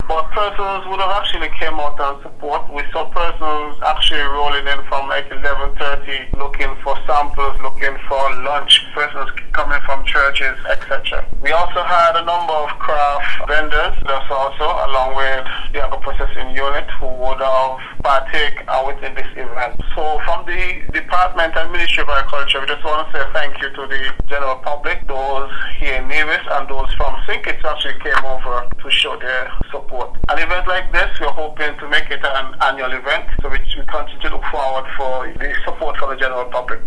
To tell us more, this is the voice of the Director of Agriculture, Mr. Randy Elliott: